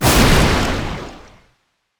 ZombieSkill_SFX
sfx_skill 15.wav